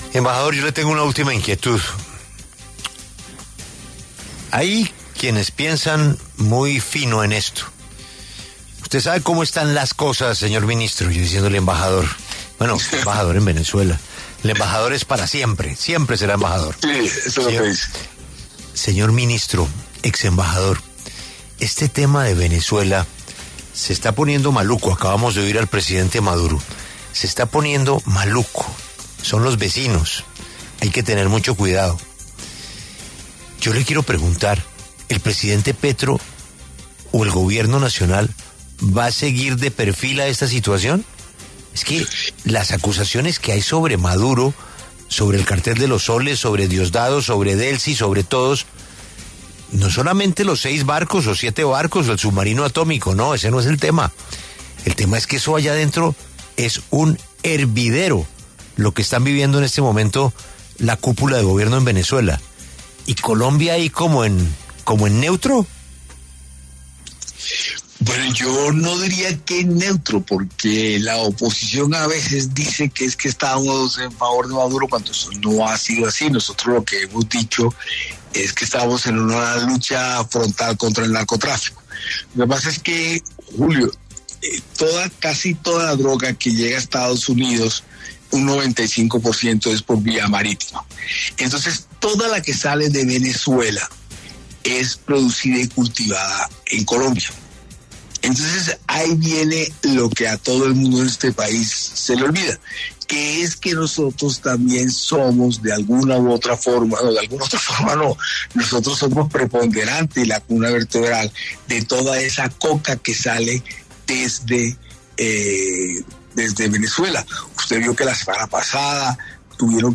Armando Benedetti, ministro del Interior, conversó con La W sobre la descertificación de Estados Unidos a Colombia en materia de lucha contra el narcotráfico, pero también se refirió a la situación de la cúpula del gobierno de Venezuela al ser acusados por EE.UU. de integrar el Cartel de los Soles.